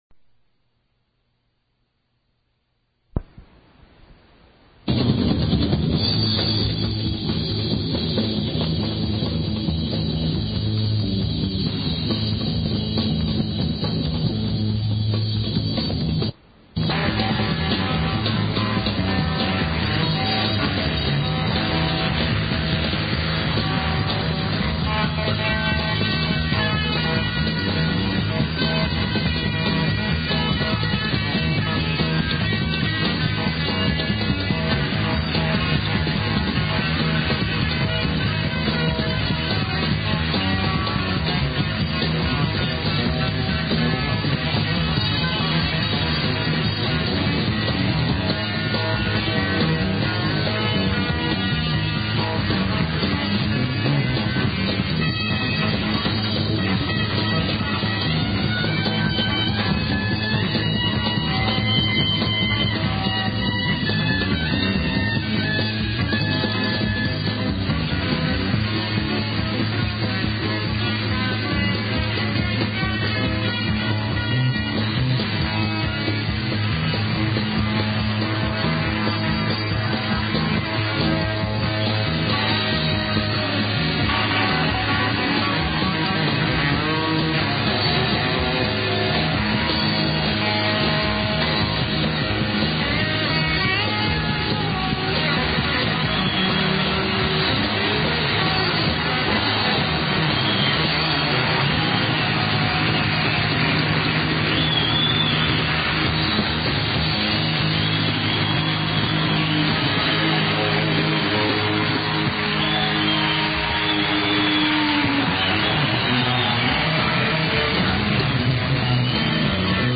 music from Ghana
slight gap